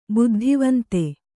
♪ buddhivante